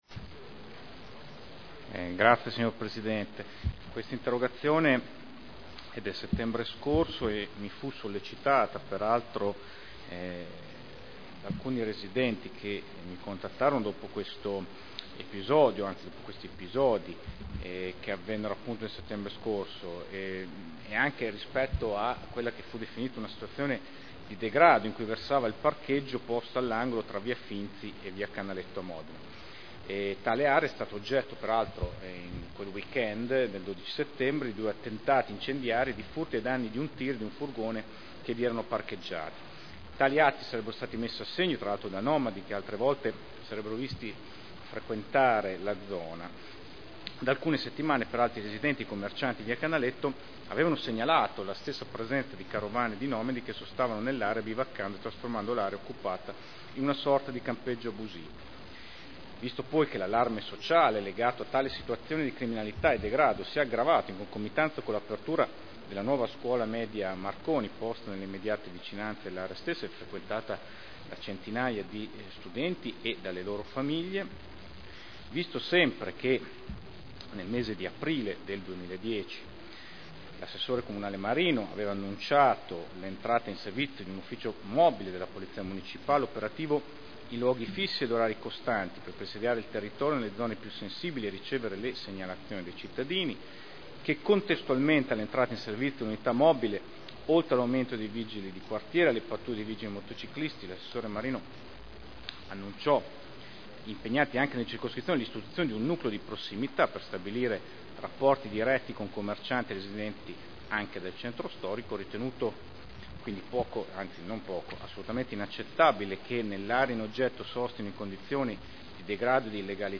Andrea Leoni — Sito Audio Consiglio Comunale